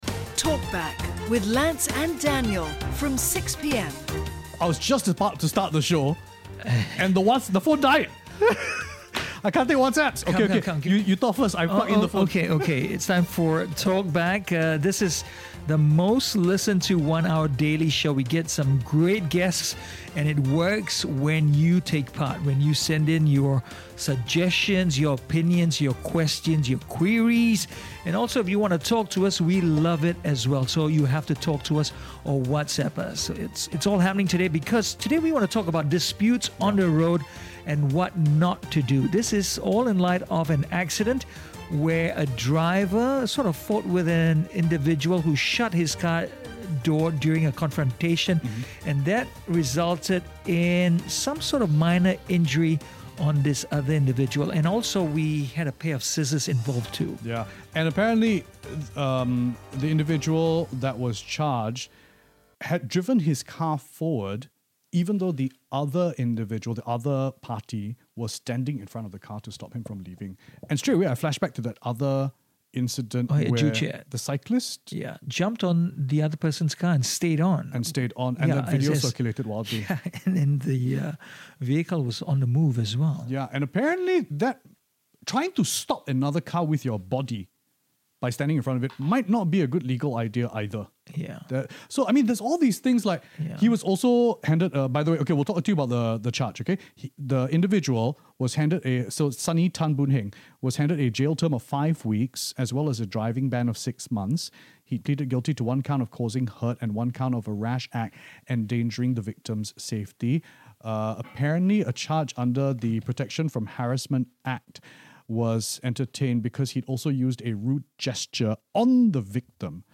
Road Rage Case Discussed on CNA938